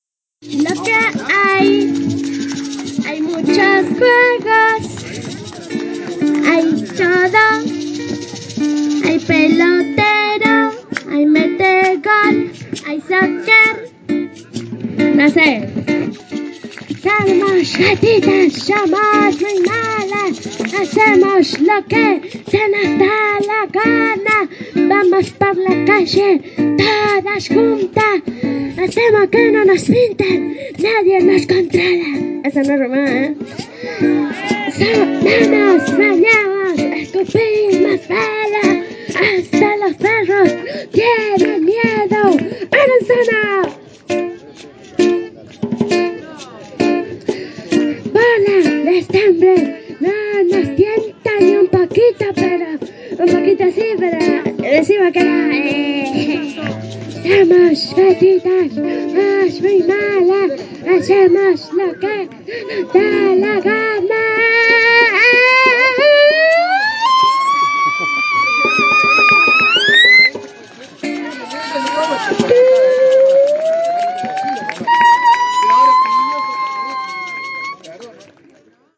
Radio del Monte estuvo presente en la fiesta que las vecinas y vecinos de Cumbre Azul organizaron para las niñas  y niños del barrio.